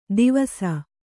♪ divasa